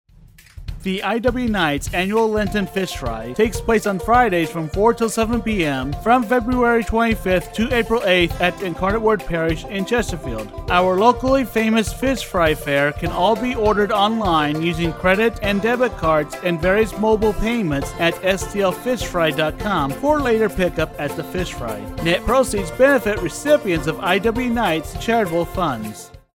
Promo Spots now airing on Covenant Network radio stations in St. Louis in 2022…  *
2022 Lenten Fish Fry Radio Spots